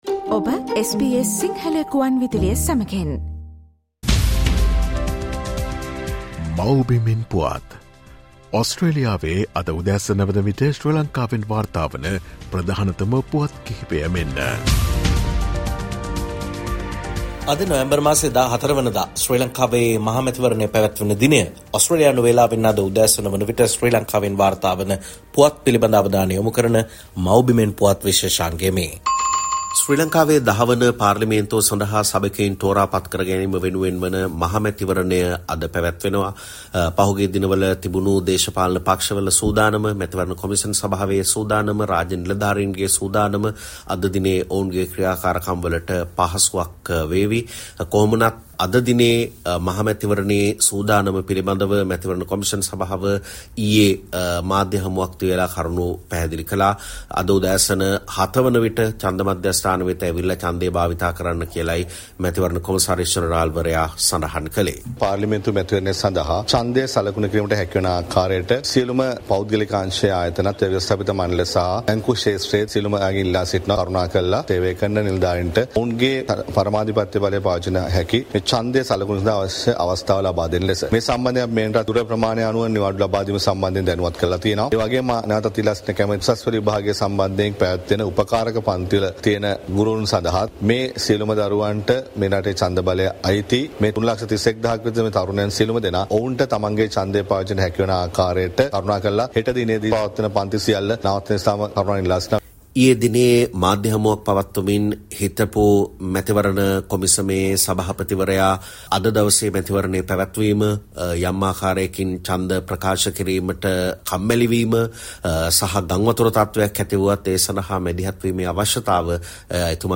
SBS Sinhala reporter and senior journalist
latest news highlights from Sri Lanka